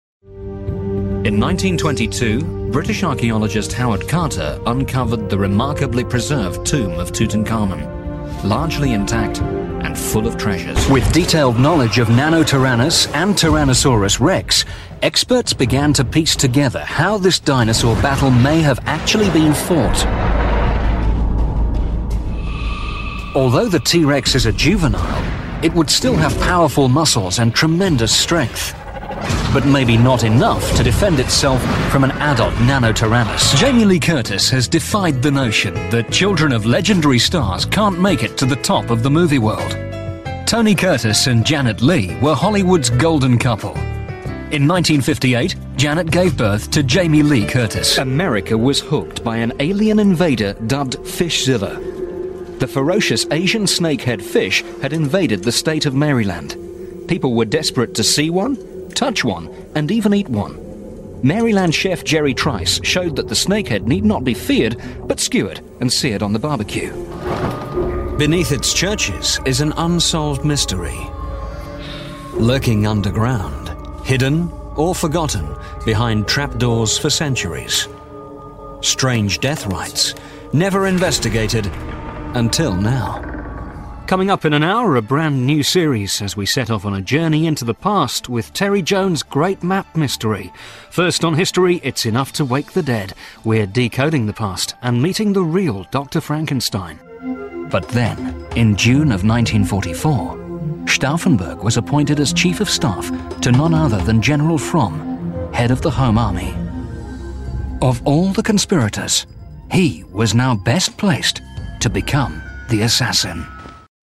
Sprecher, Werbesprecher
Commercial Demo